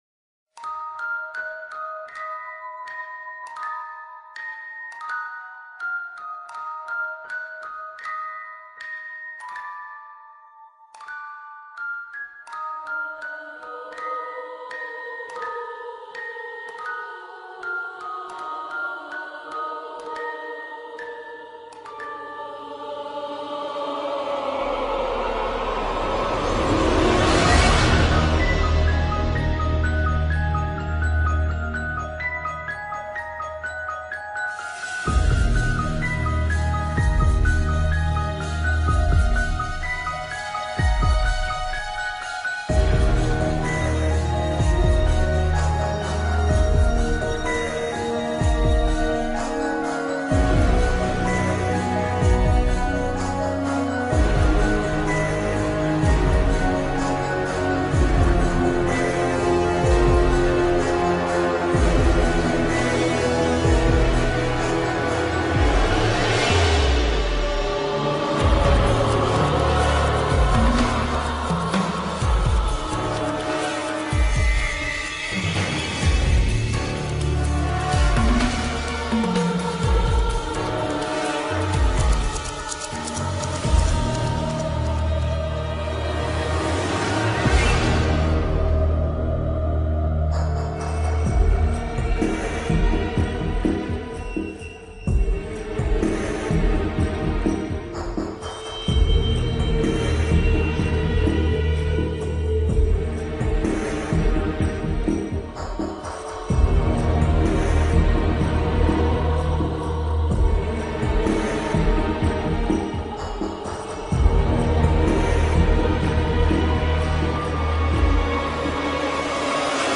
LAip0PScMLz_musique-qui-fait-peur.mp3